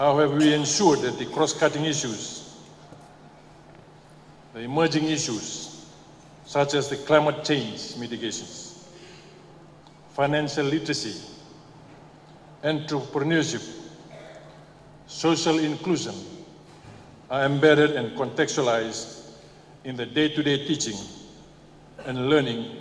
Speaking at the Fiji Head Teachers Annual Conference in Suva yesterday, Radrodro emphasized the need to bolster students’ critical thinking and problem-solving abilities.